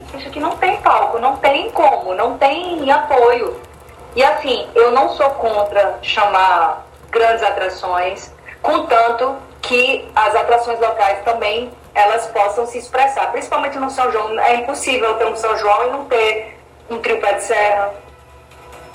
Durante entrevista ao programa Frente a Frente, da TV Arapuan, nesta segunda-feira (04/12), disse que é preciso haver um equilíbrio no número de atrações que são contratadas, ressaltando que não é contra a participação das grandes atrações.